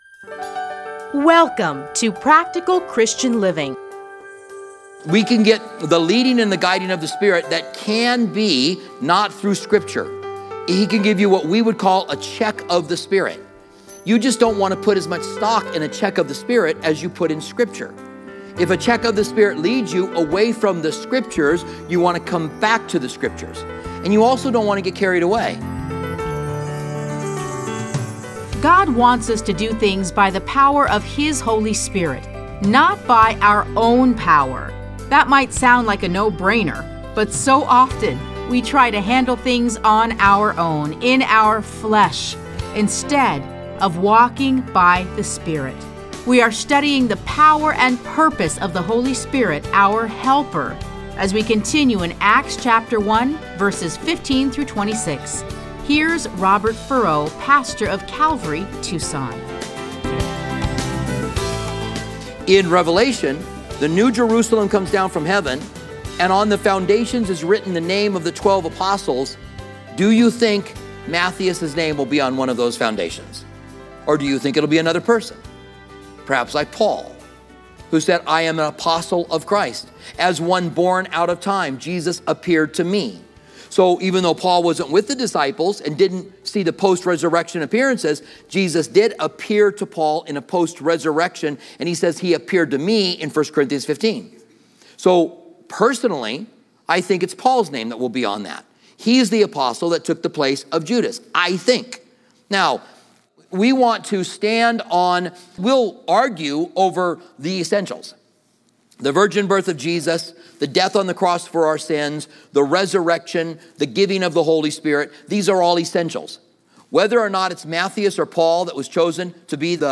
Listen to a teaching from Acts 1:15-26.